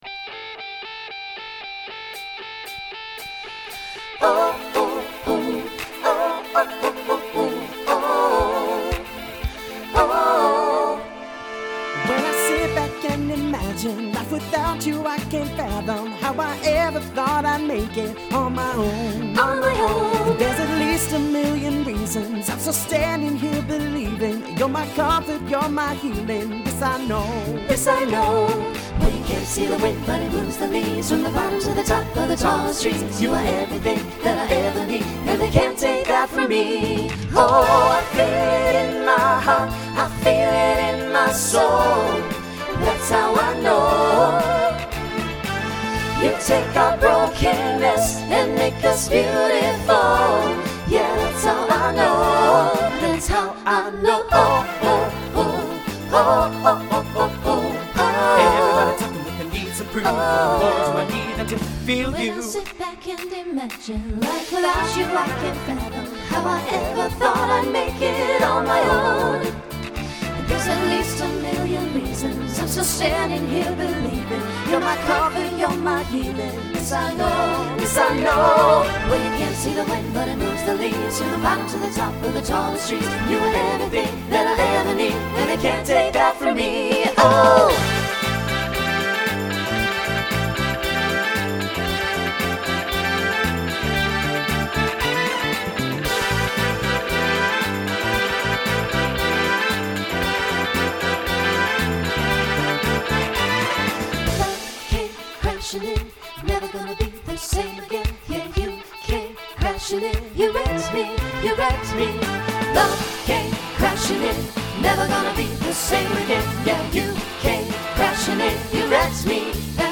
SATB Instrumental combo
Pop/Dance
Mid-tempo